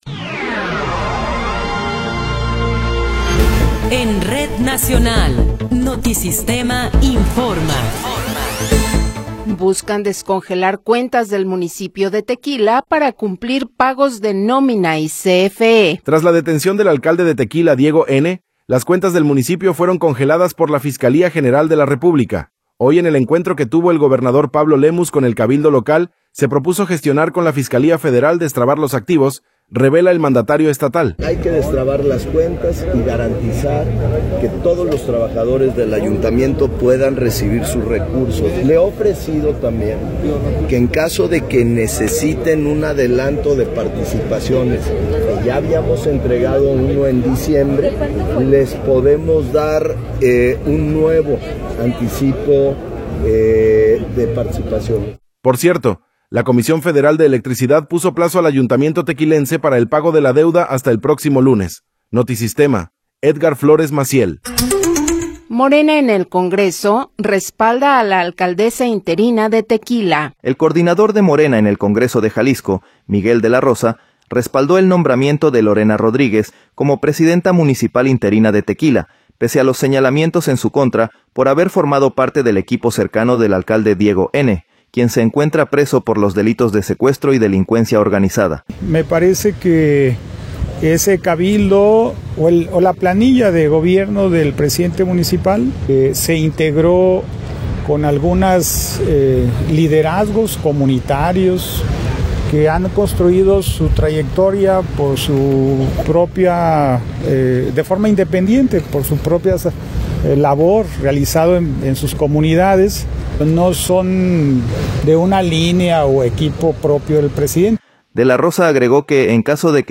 Noticiero 15 hrs. – 10 de Febrero de 2026